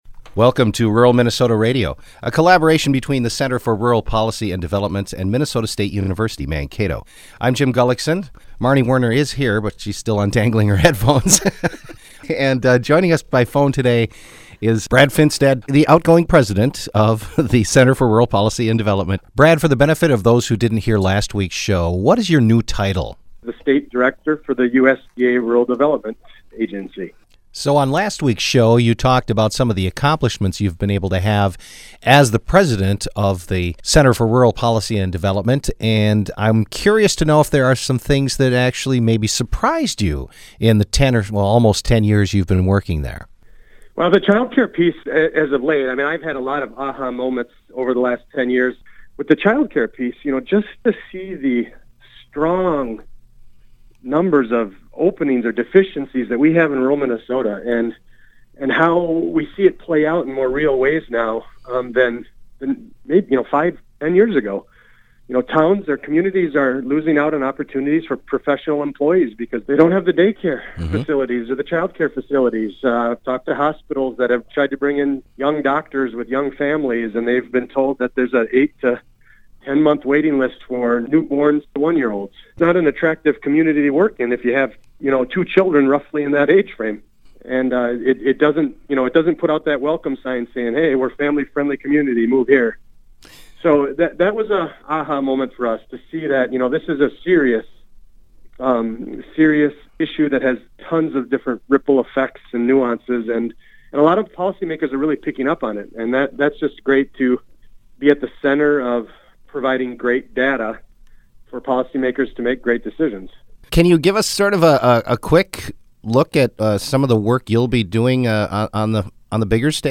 This week we have part 2 of our interview with the Center's outgoing president Brad Finstad as he heads off to take over as state director of USDA Rural